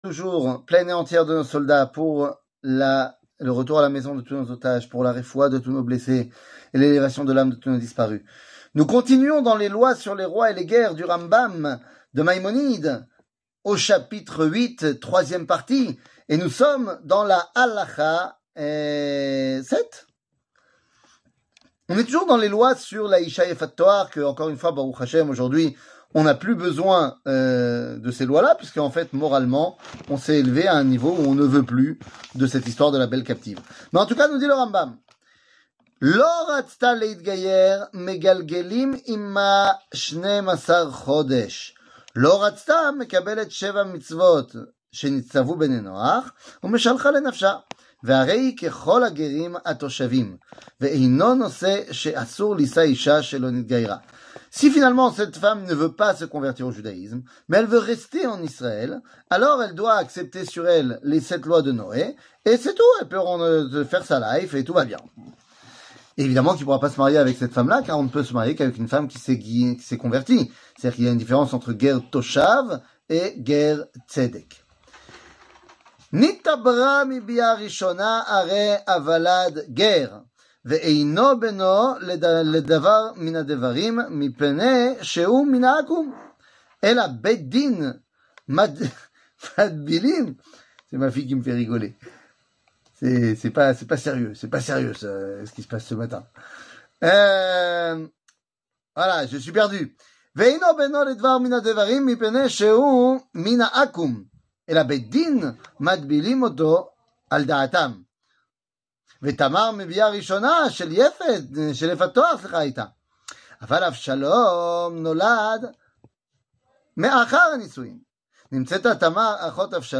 שיעור מ 01 ינואר 2024